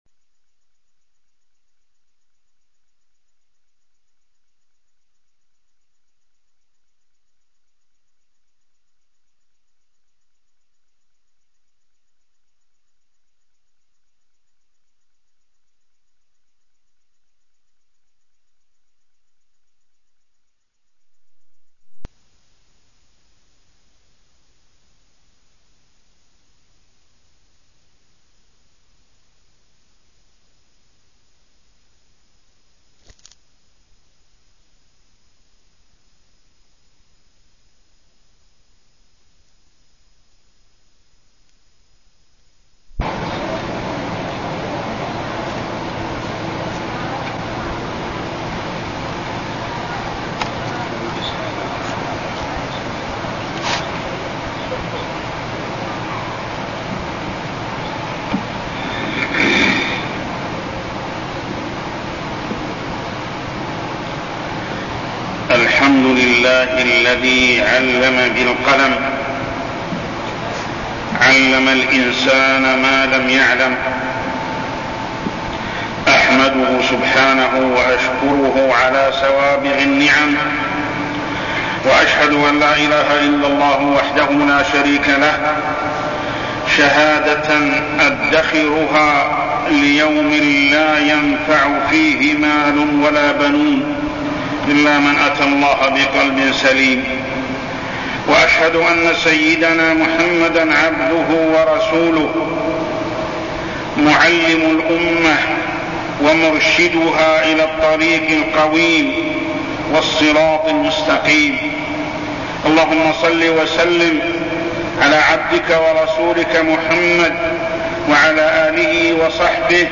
تاريخ النشر ٨ ربيع الثاني ١٤١٤ هـ المكان: المسجد الحرام الشيخ: محمد بن عبد الله السبيل محمد بن عبد الله السبيل طلب العلم والعمل